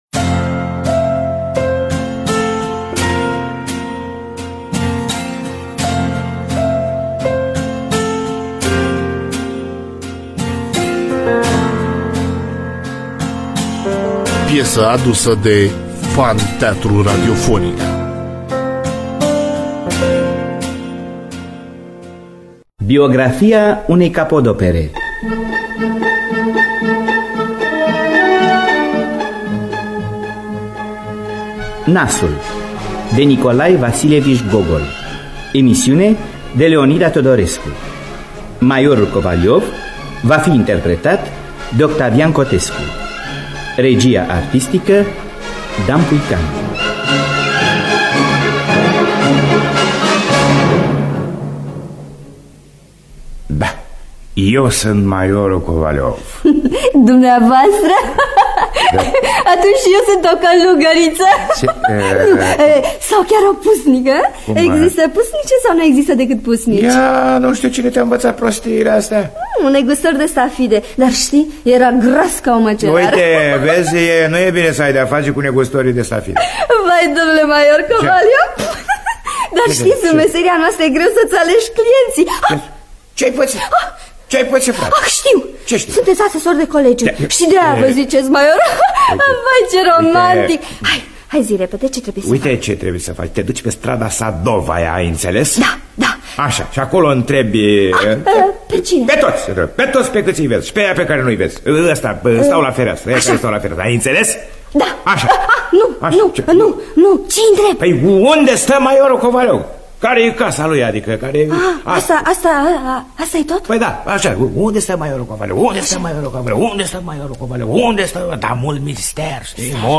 Biografii, Memorii: Nikolai Vasilievici Gogol – Nasul (1978) – Teatru Radiofonic Online